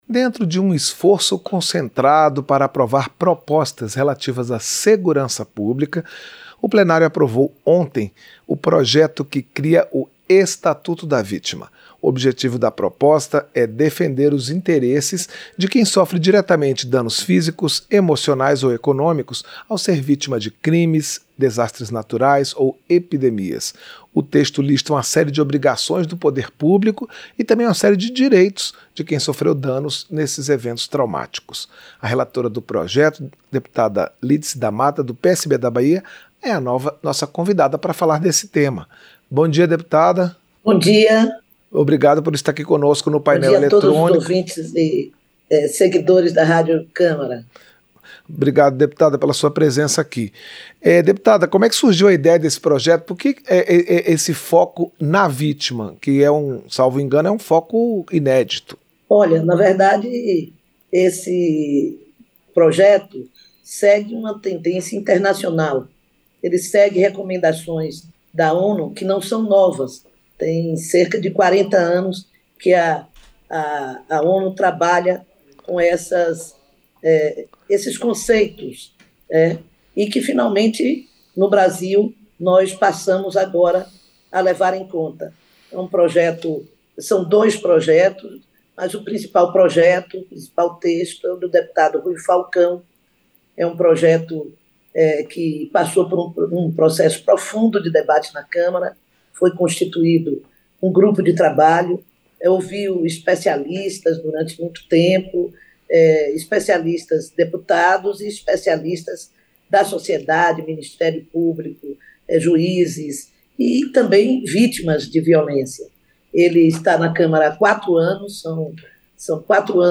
Entrevista - Dep. Lídice da Mata (PSB-BA)